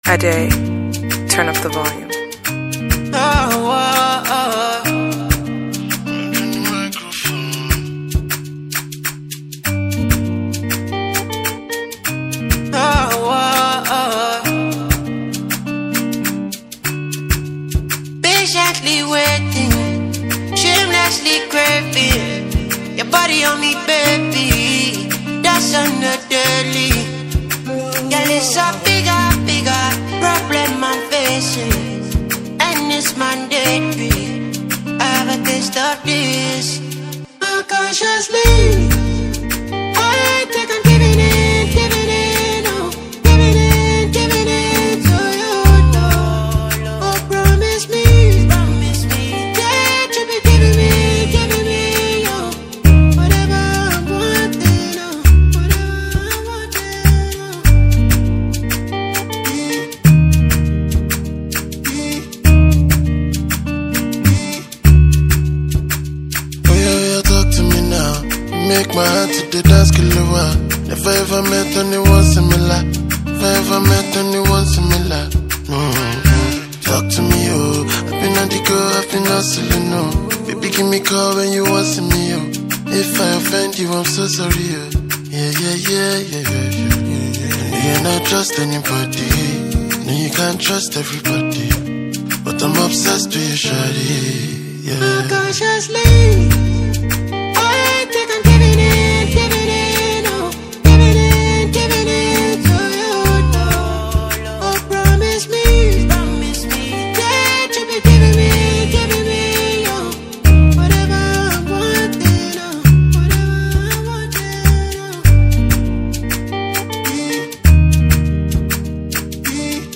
Nigerian Alte singer
fast rising singer and songwriter